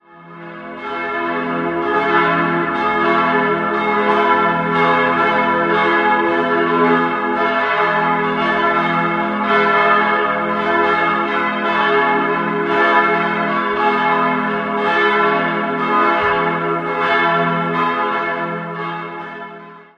Der Kirchturm kam erst einige später hinzu. 4-stimmiges Salve-Regina-Geläute: f'-a'-c''-d'' Die Glocken wurden im Jahr 1980 von Rudolf Perner in Passau gegossen.